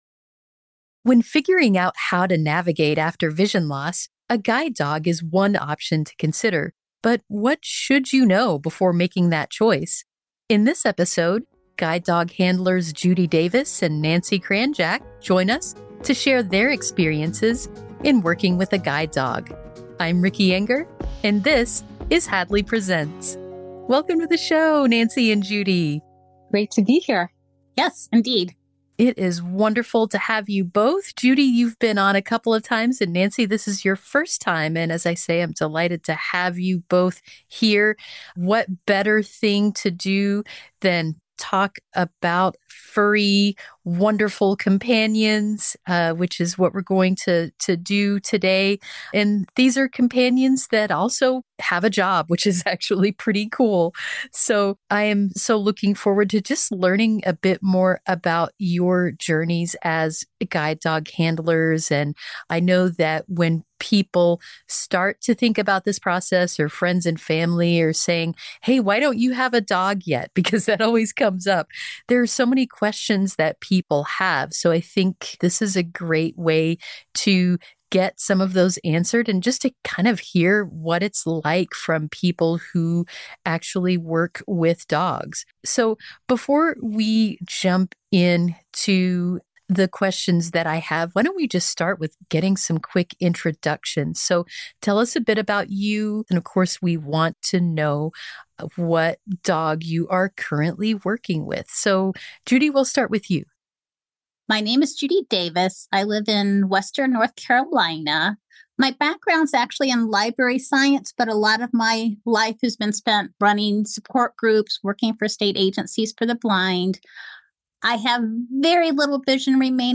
An episode of the Hadley Presents: A Conversation with the Experts audio podcast